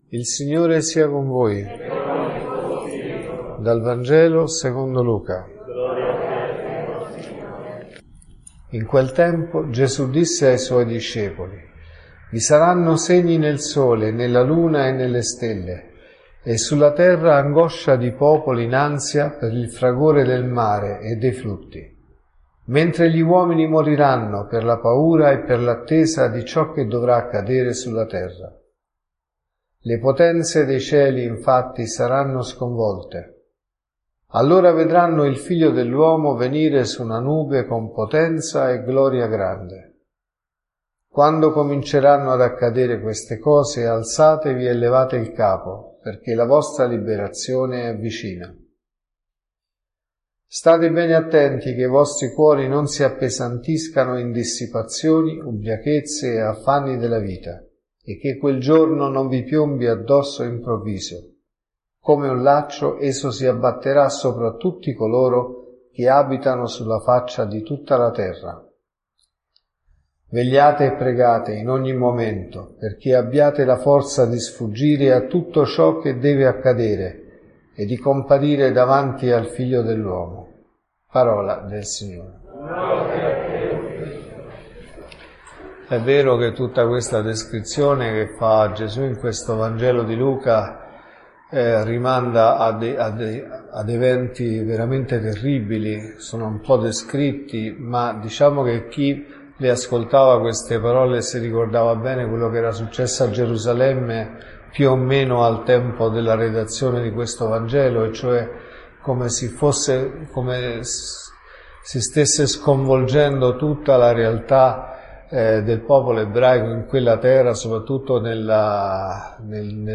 La vostra liberazione è vicina.(Messa mattino e sera)